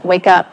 synthetic-wakewords
ovos-tts-plugin-deepponies_Kim Kardashian_en.wav